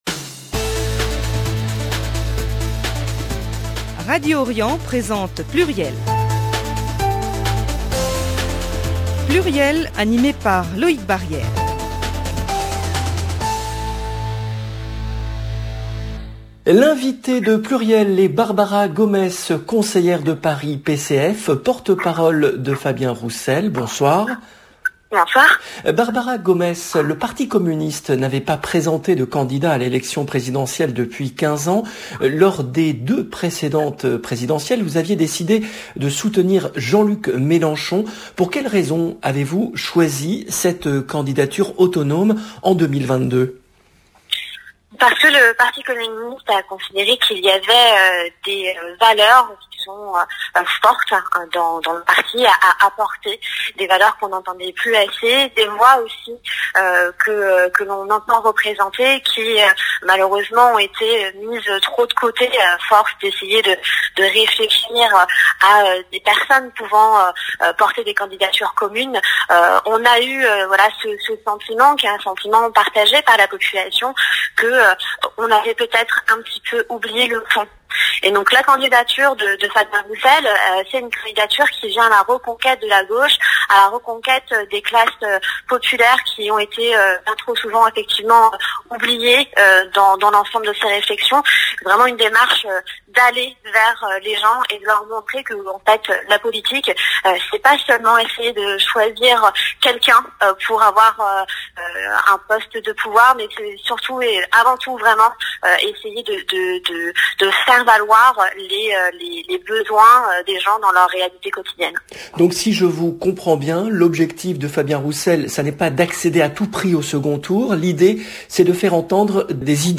Emission diffusée le vendredi 15 octobre 2021